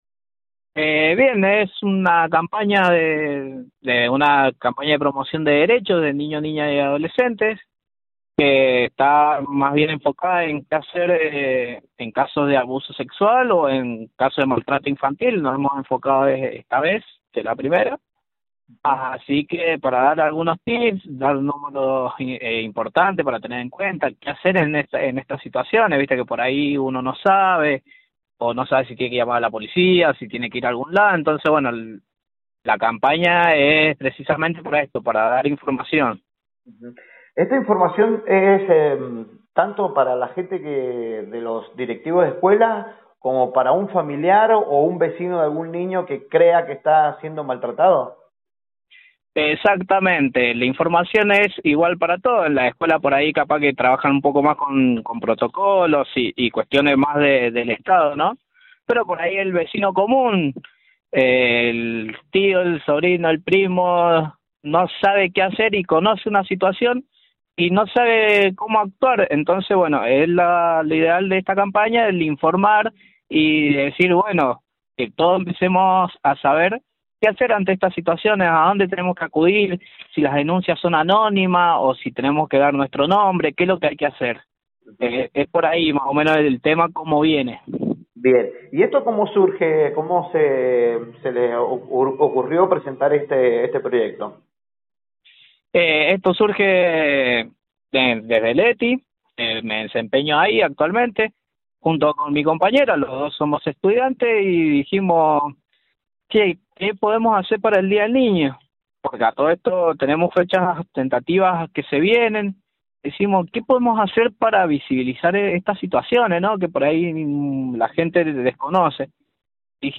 En Proyección 103, Entrevistamos